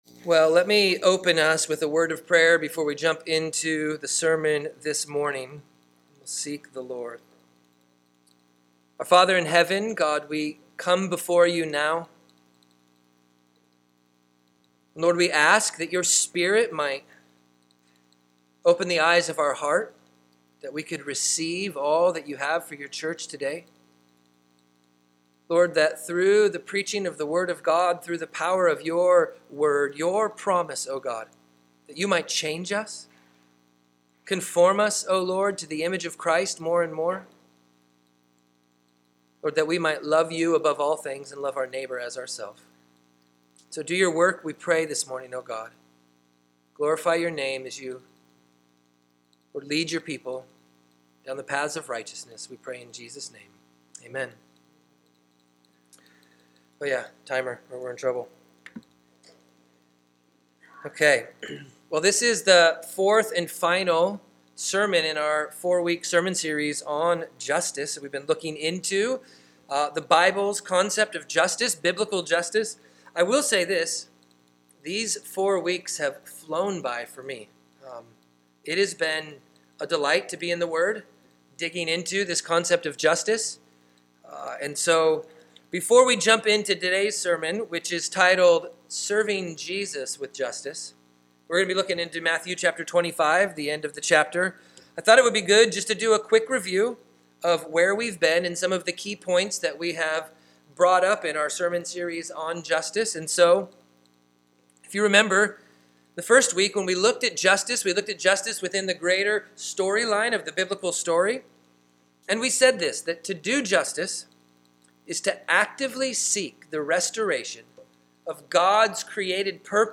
This is our fourth and final sermon in our series on biblical justice.